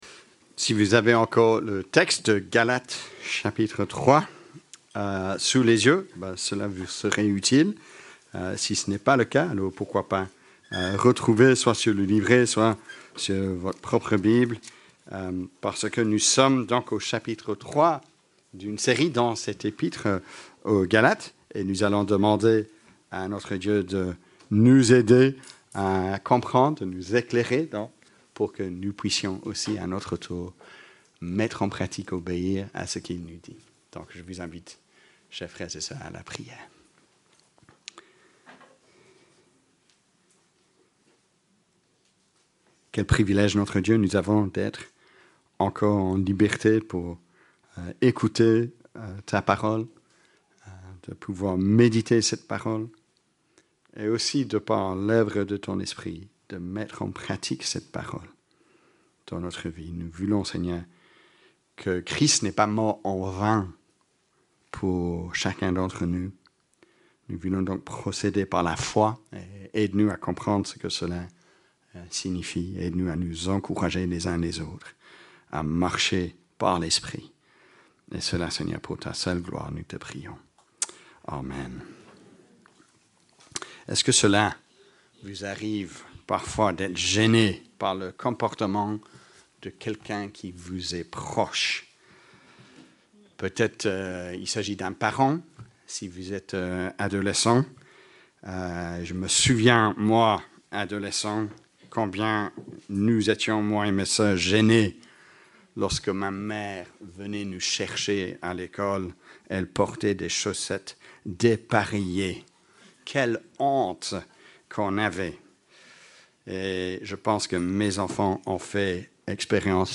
predication-17-03.mp3